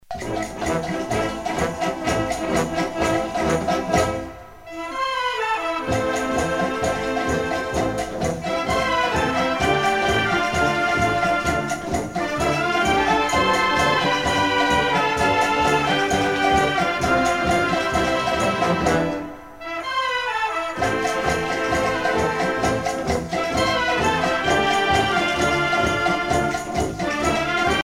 danse : samba